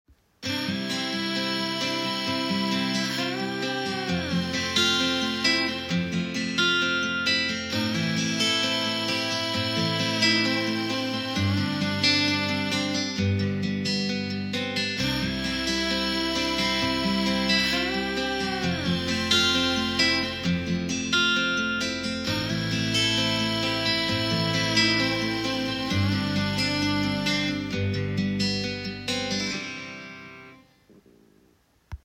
Below are two comparative recordings of music and speech.
MUSIC
musik-headset-apple.m4a